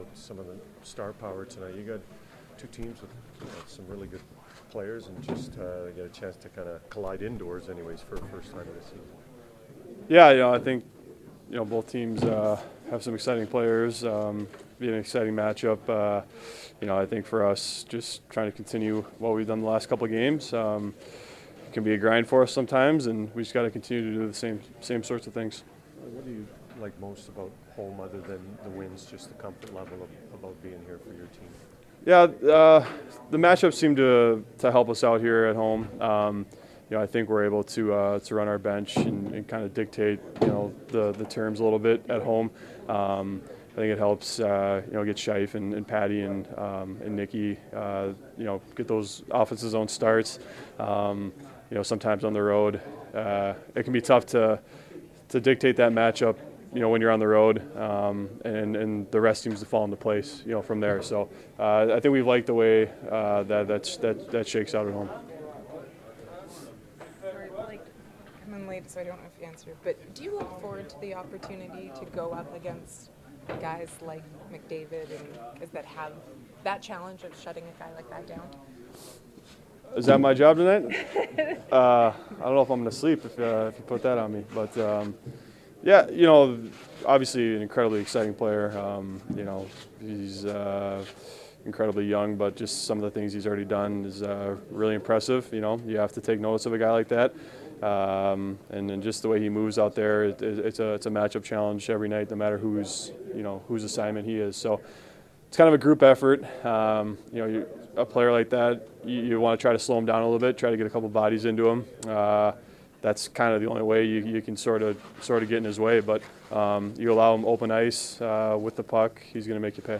Player pre-game audio:
All audio courtesy of TSN 1290 Winnipeg.